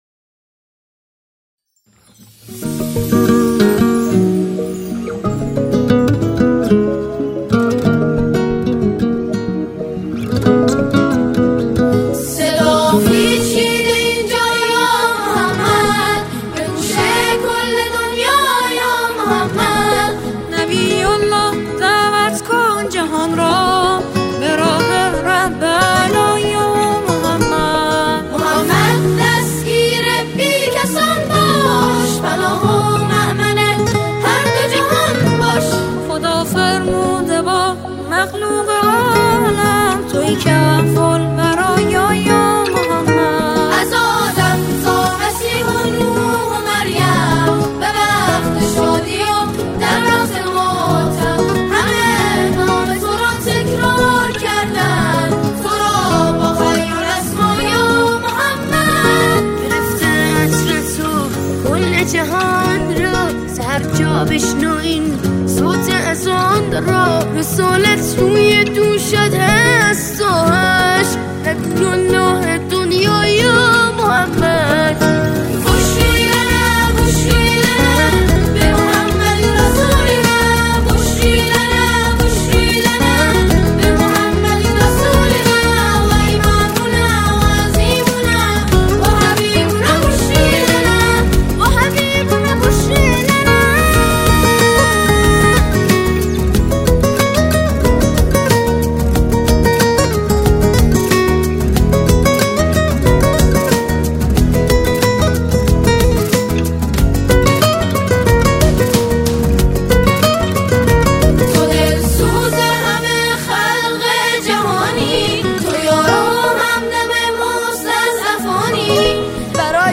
آنها در این قطعه، شعری را درباره عید مبعث همخوانی می‌کنند.